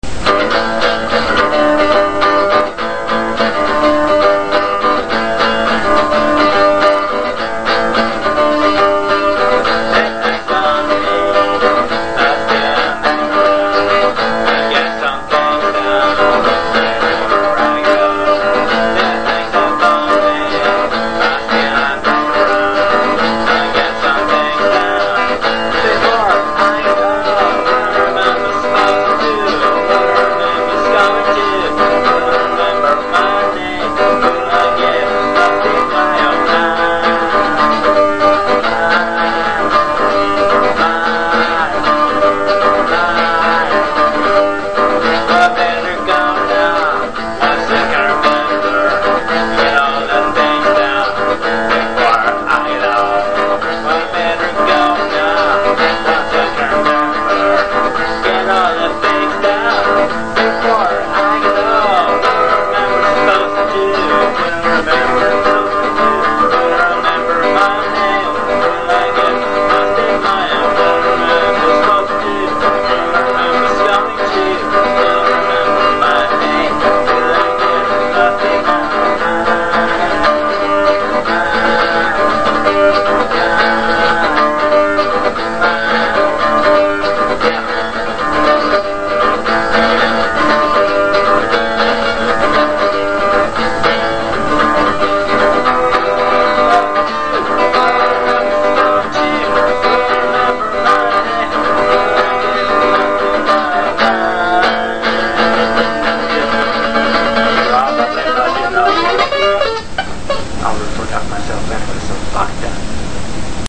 Some really noizy and cool wave-files done by me (in first take with fuck-ups and whatevers included):
ACOUSTIC
An old song, but this time Acoustic...